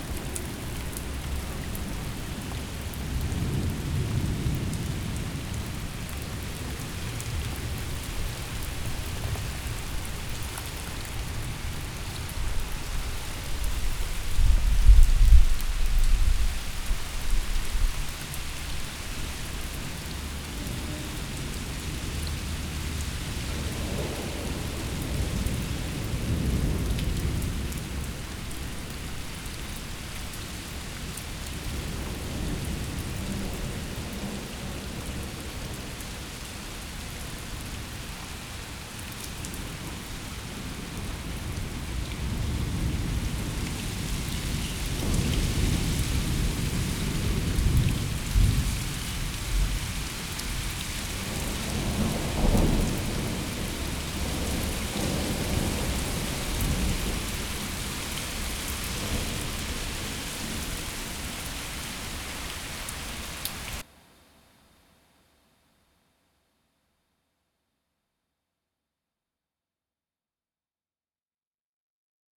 Index of /audio/samples/SFX/IRL Recorded/Rain - Thunder/
Rain 3.wav